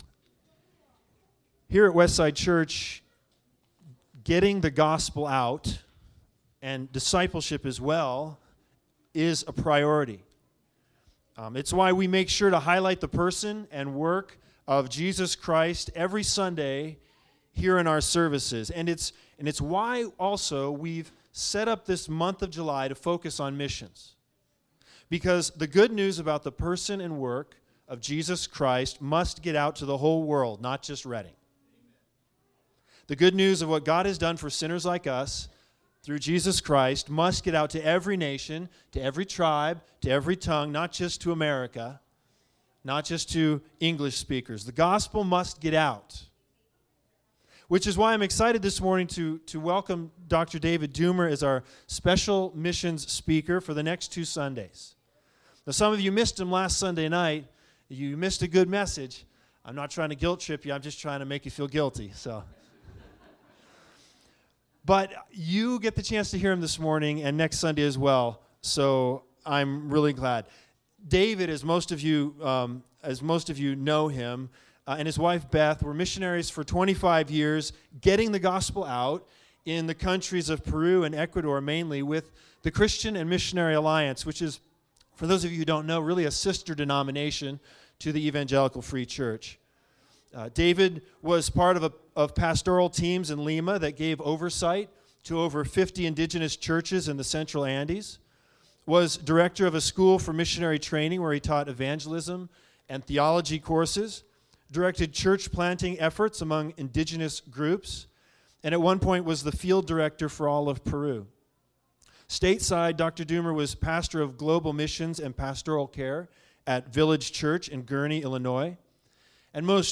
2 Kings 7:1-20 Service Type: Special Sermons The Big Idea